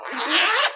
BackpackOpened.wav